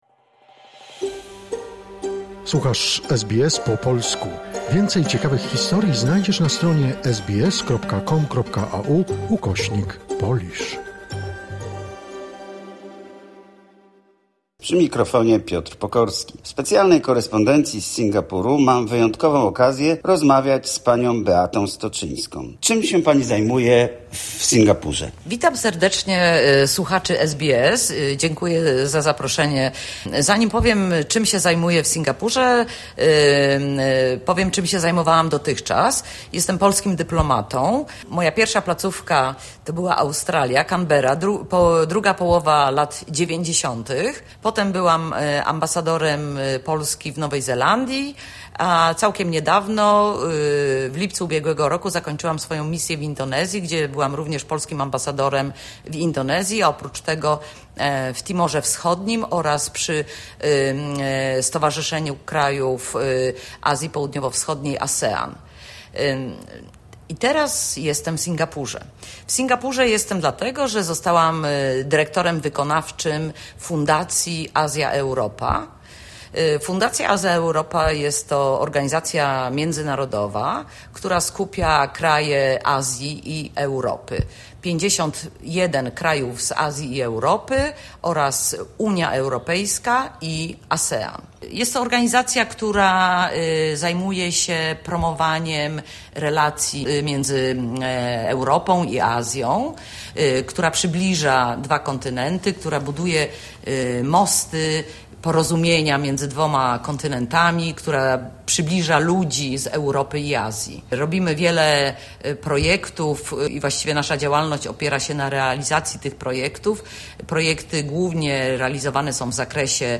O budowaniu relacji między Europą, Azją i Australią mówi Beata Stoczyńska. Dyplomatka, była Ambasador RP w Nowej Zelandii i Indonezji oraz pracownik dyplomatyczny w Australii, obecnie dyrektor wykonawczy Fundacji Azja-Europa podkreśła ogromną rolę fundacji, która umacnia więzi między kontynentami poprzez kulturę, sztukę, gospodarkę i naukę.